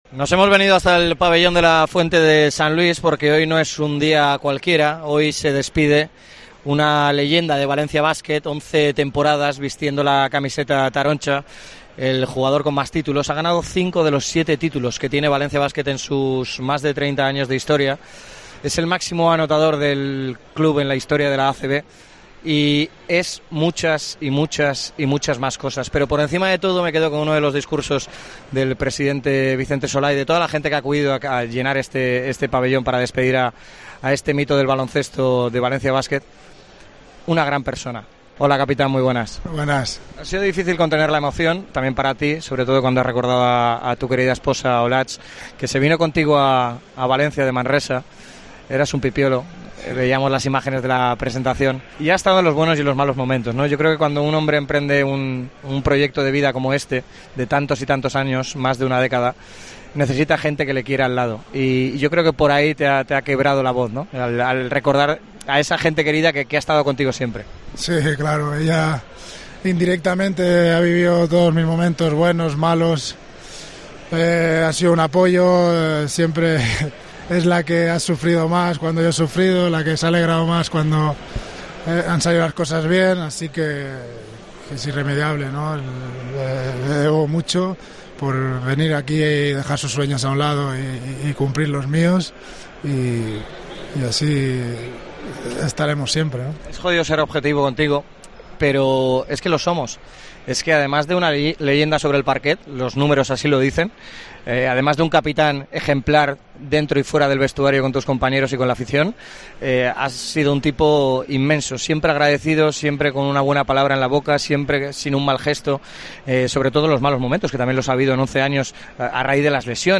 Entrevista a Rafa Martínez en Deportes COPE MÁS Valencia